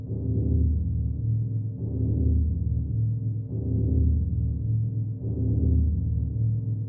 ships / rooms / deck.wav
deck.wav